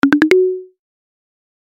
• Качество: Хорошее
• Категория: Рингтон на смс